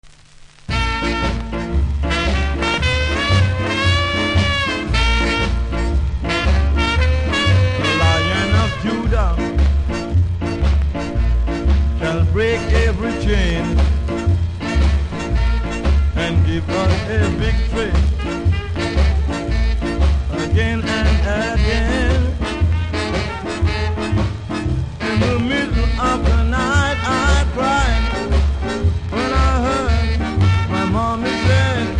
CONDITION：VG ( WOL )
細かいキズ多めでノイズもそこそこありますので試聴で確認下さい。